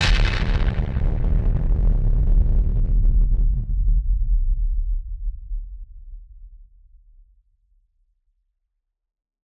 BF_DrumBombB-05.wav